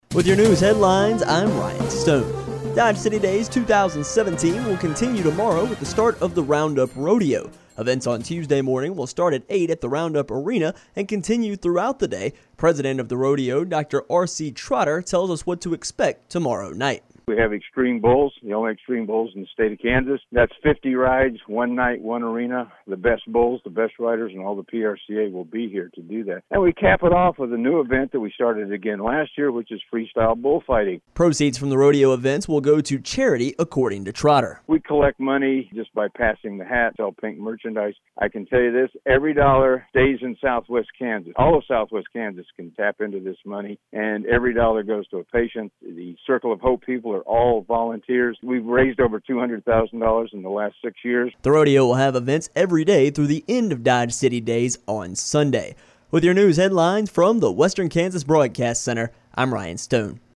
Radio Story